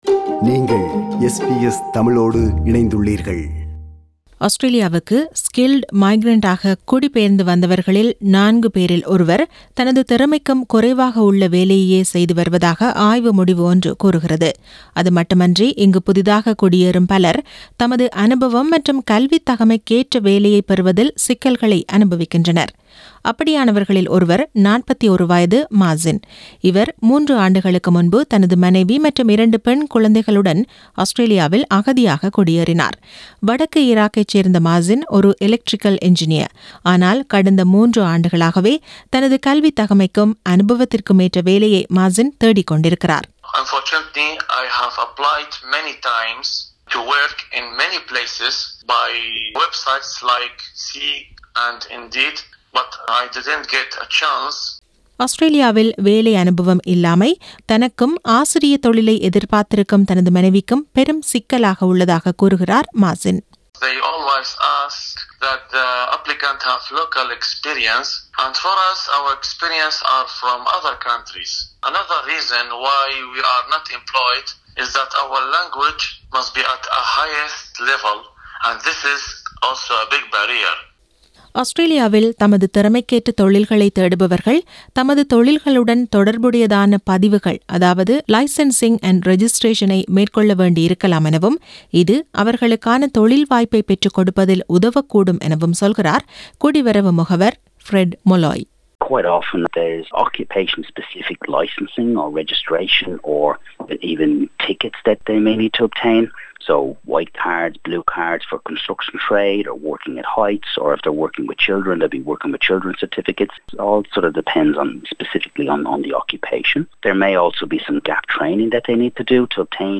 ஆஸ்திரேலியாவுக்கு புதிதாக குடியேறிய பலர் தமது அனுபவம் மற்றும் கல்வித்தகைமைக்கேற்ற வேலையைப் பெறுவதில் சிக்கல்களை அனுபவிக்கின்றனர். இதற்கான தீர்வுகளை உள்ளடக்கிய விவரணம்.